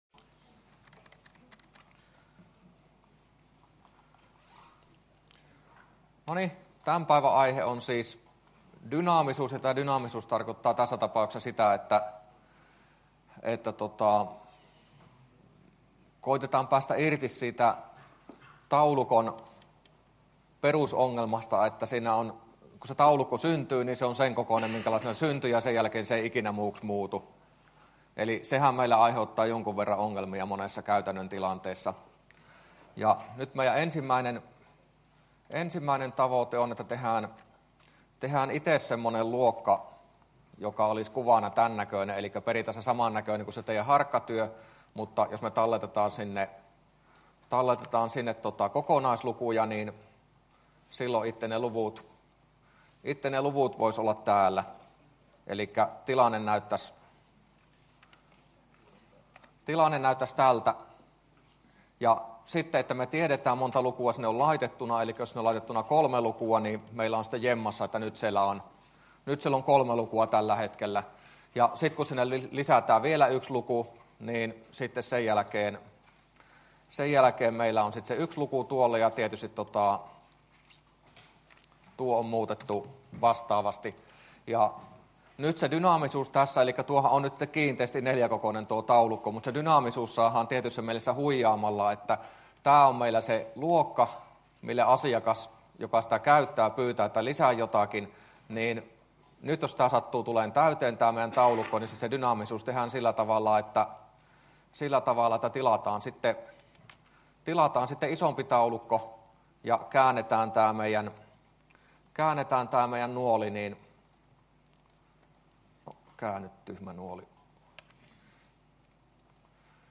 luento16a